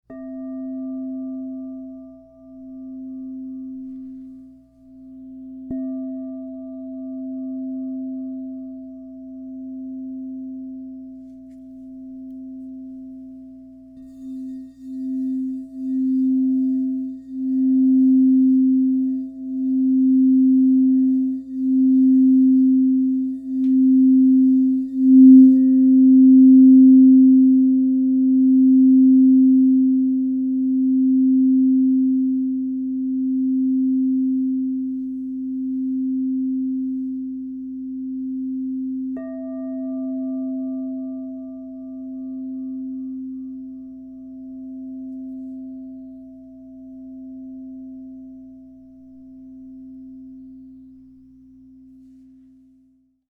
Larimar, Rose Quartz, Platinum 10" C 0 Crystal Tones singing bowl
Immerse yourself in the harmonizing resonance of the Crystal Tones® Larimar Triple Alchemy 10 inch C True Tone Singing Bowl, resonating at a perfect C 0 True Tone to inspire emotional clarity, balance, and spiritual renewal.
The expansive 10-inch size delivers rich and resonant tones, making it ideal for group sound healing sessions, personal meditation, or enhancing sacred spaces.